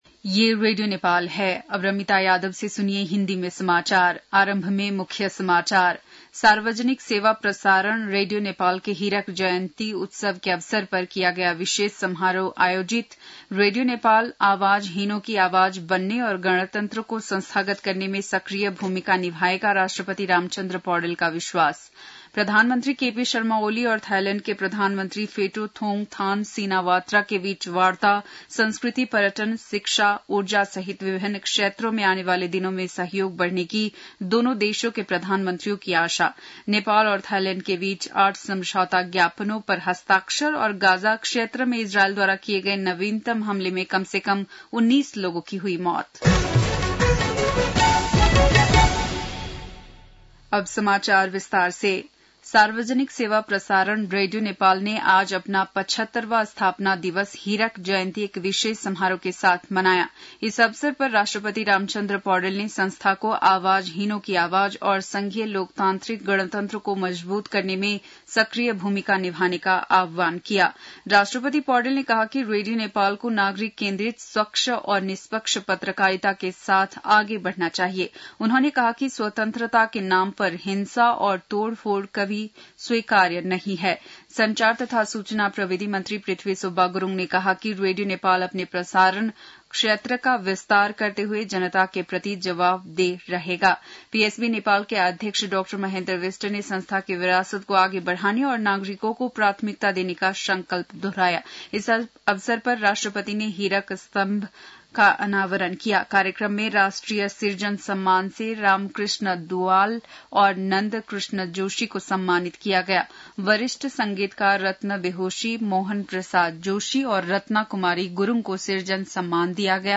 बेलुकी १० बजेको हिन्दी समाचार : २० चैत , २०८१
10-pm-hindi-news-1.mp3